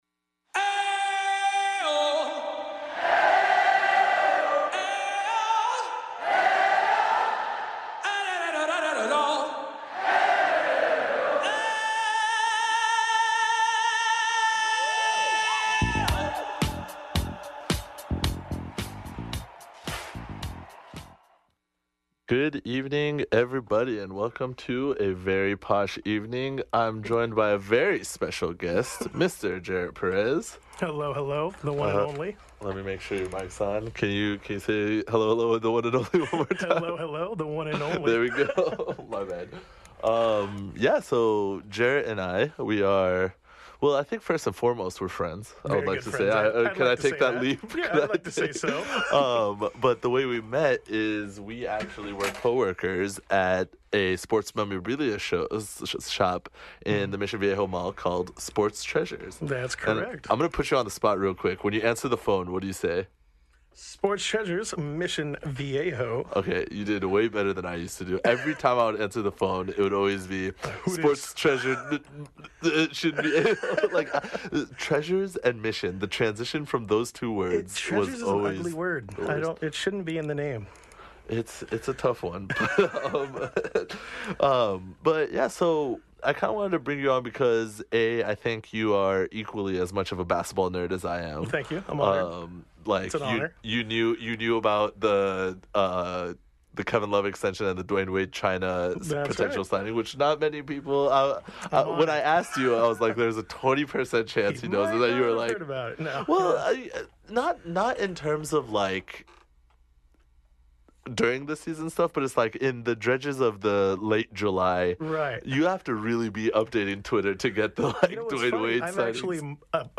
*This is a recording of a live show from the non-profit station, KXSC.